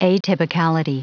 Prononciation du mot atypicality en anglais (fichier audio)
Prononciation du mot : atypicality